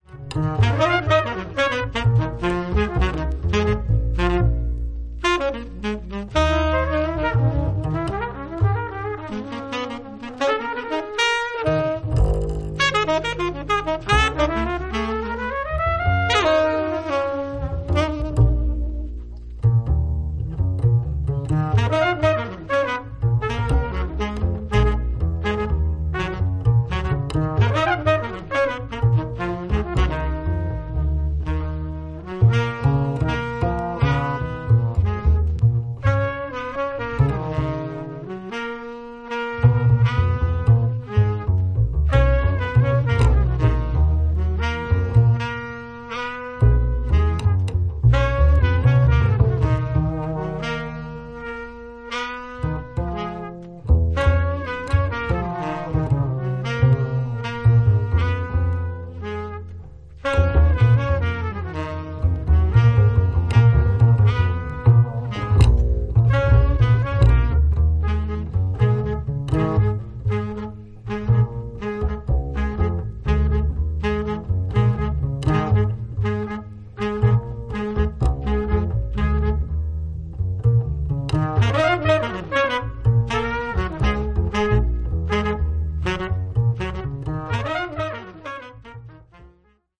アンティル諸島出身のベーシスト/シンガー